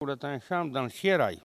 Elle provient de Saint-Hilaire-de-Riez.
Locution ( parler, expression, langue,... )